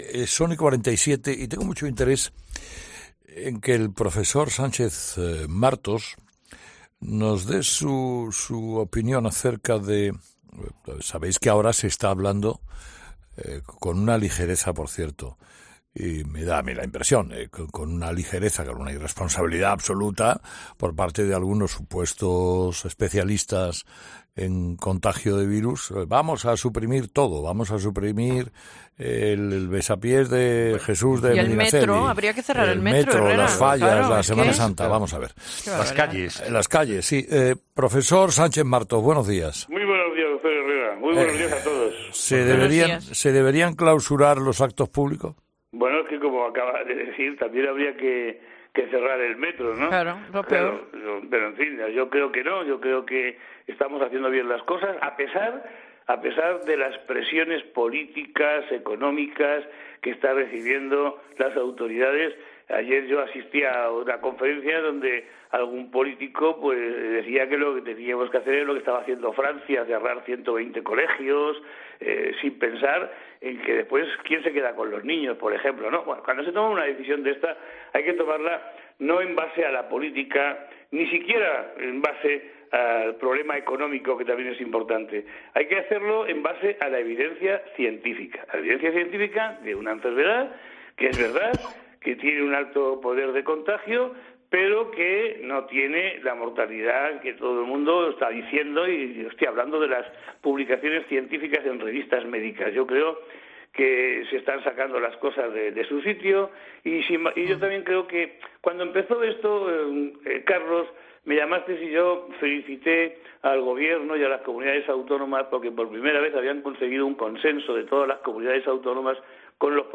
Herrera en COPE